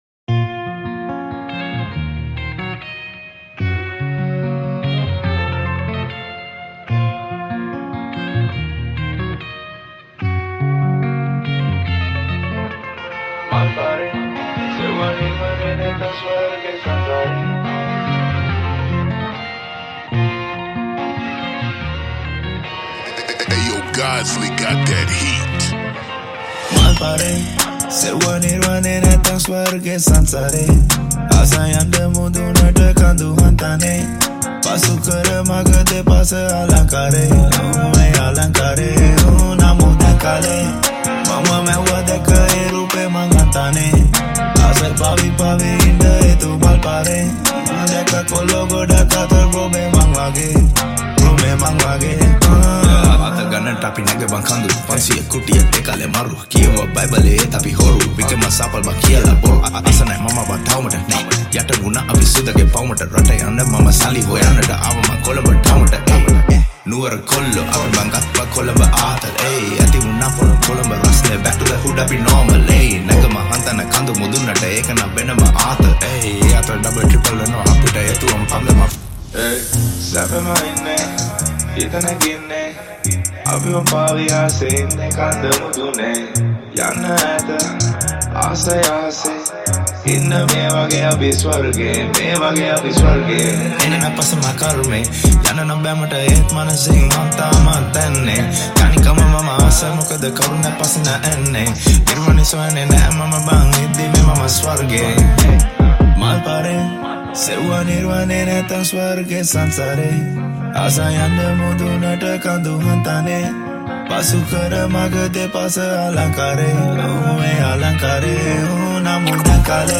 High quality Sri Lankan remix MP3 (3.8).
Rap